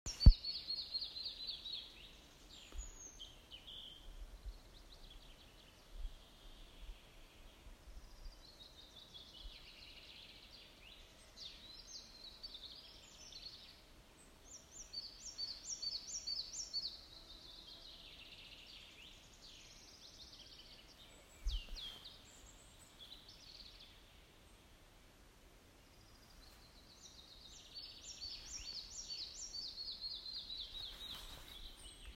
малая мухоловка, Ficedula parva
Ziņotāja saglabāts vietas nosaukumsGauja Ērgļu klintis
СтатусПоёт